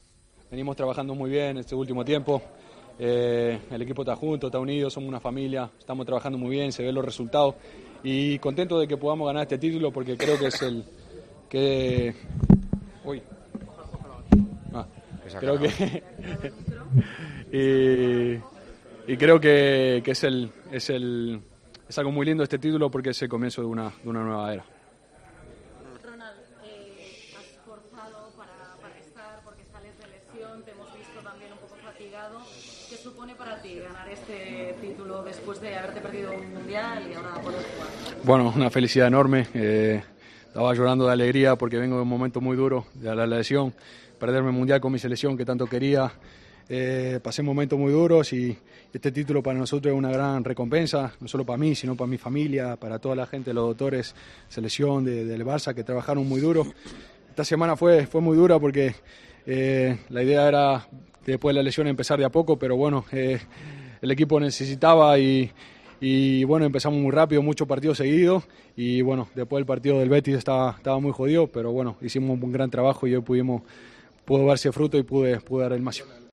El central uruguayo del Barcelona atendió a los medios de comunicación en la zona mixta y se mostró feliz por el título.